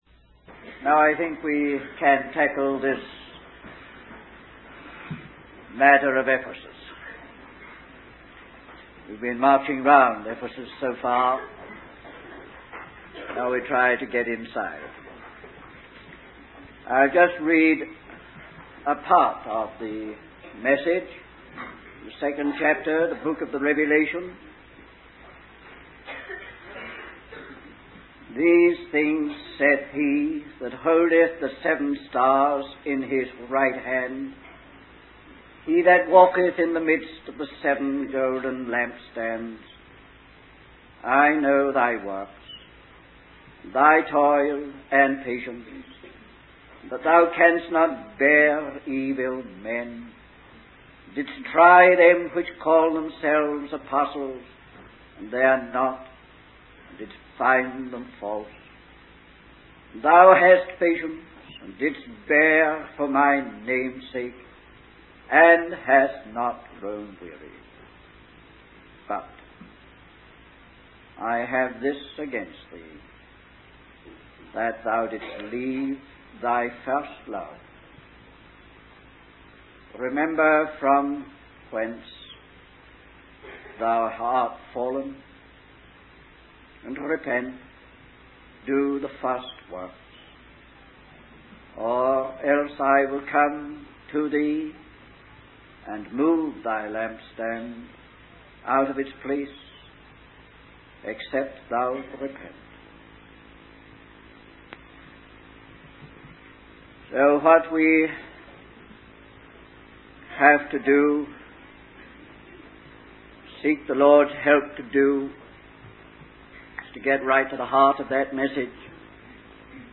In this sermon, the speaker emphasizes the importance of true heart appreciation for God and what He has done for us. The focus is on the book of Revelation, specifically chapters four and five, which depict a worshiping church in heaven.